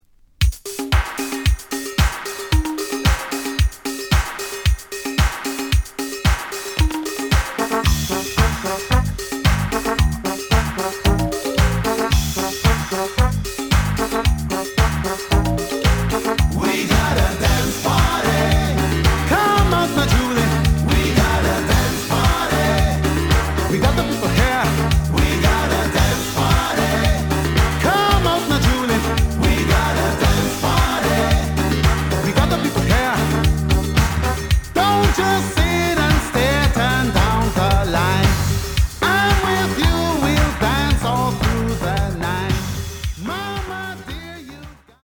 試聴は実際のレコードから録音しています。
●Genre: Reggae